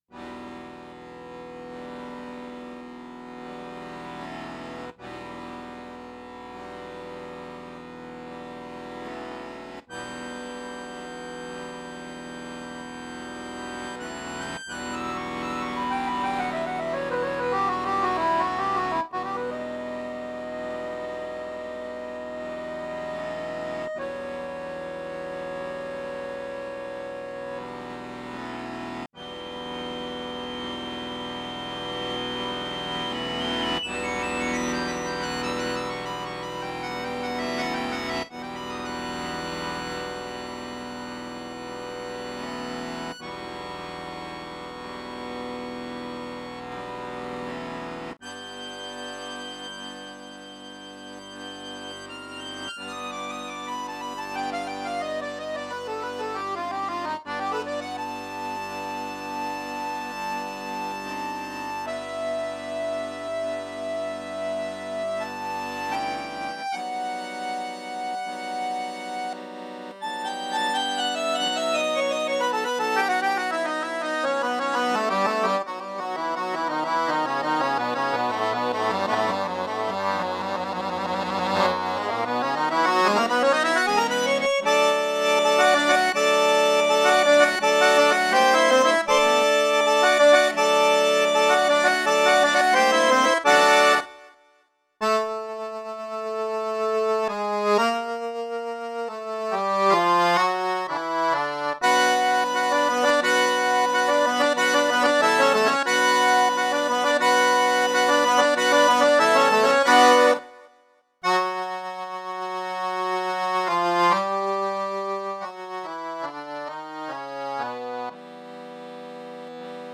Solo performances